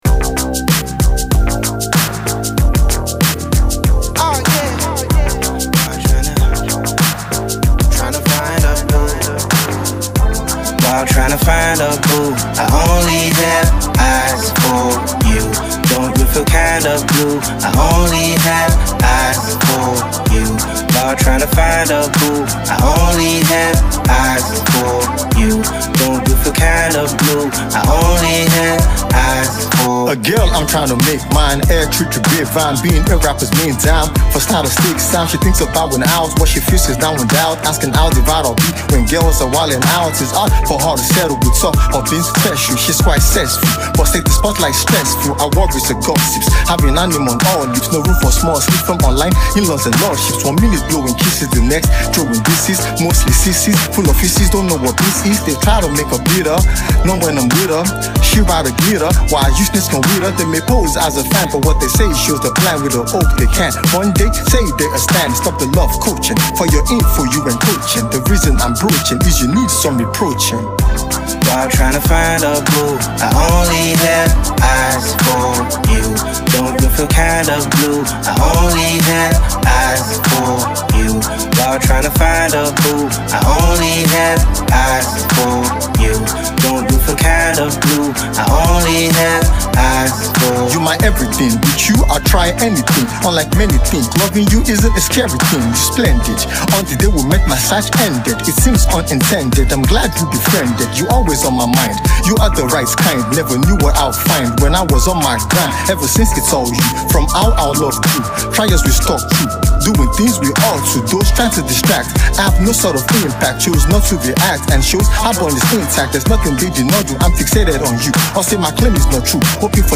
Nigerian hip-hop
soulful sounds